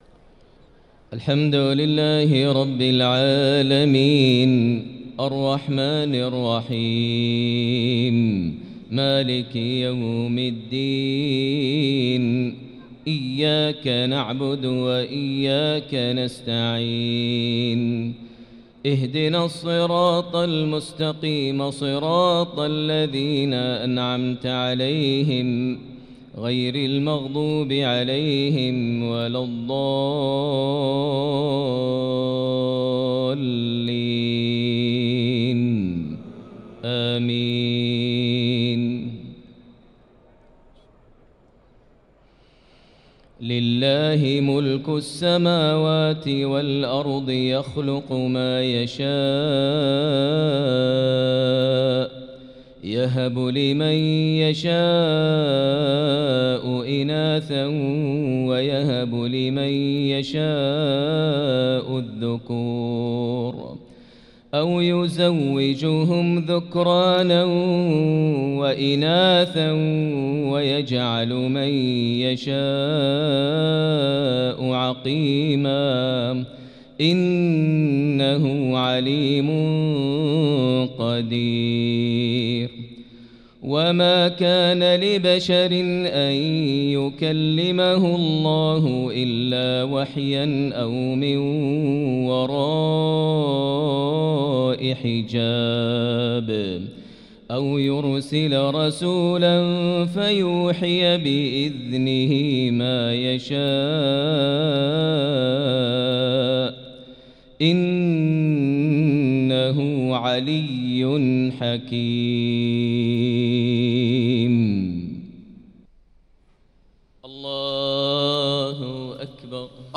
صلاة العشاء للقارئ ماهر المعيقلي 29 رمضان 1445 هـ
تِلَاوَات الْحَرَمَيْن .